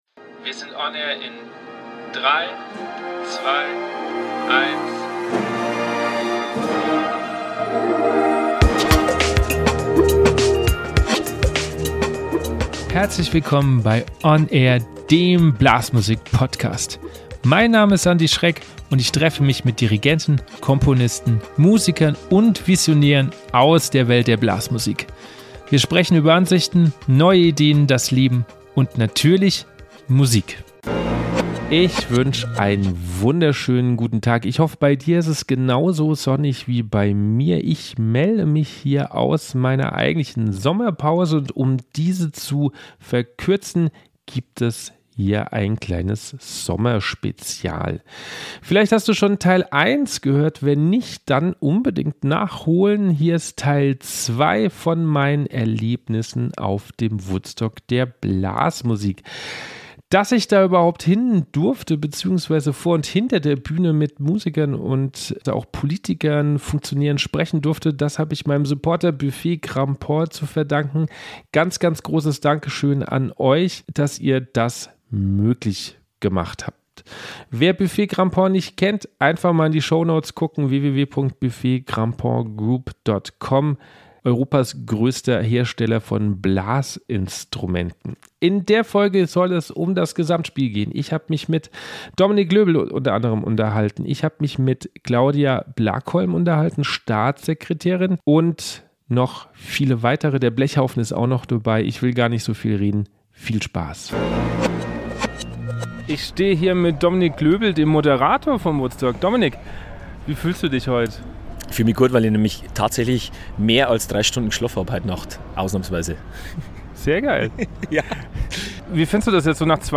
Ich war als rasender Reporter auf dem Woodstock der Blasmusik 2022 unterwegs und durfte allerhand Menschen vor und hinter der Bühne treffen. Im zweiten Teil geht es um das Gesamtspiel.